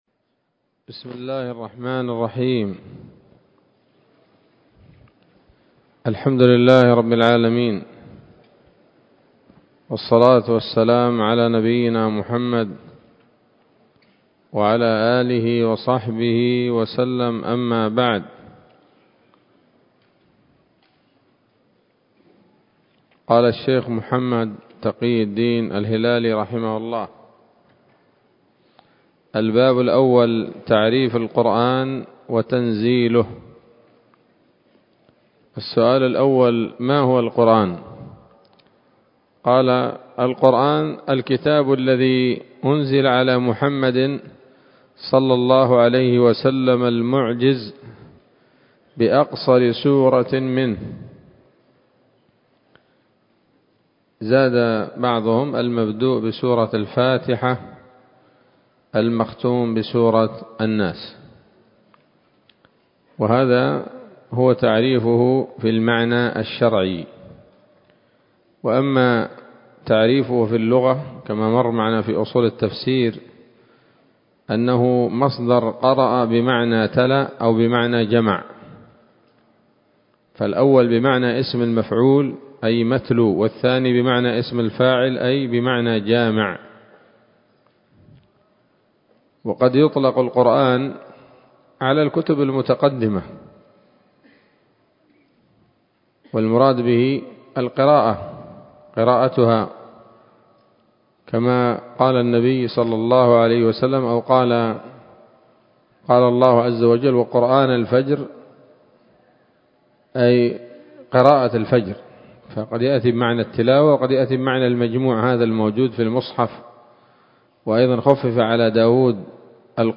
الدرس الثالث من كتاب نبذة من علوم القرآن لـ محمد تقي الدين الهلالي رحمه الله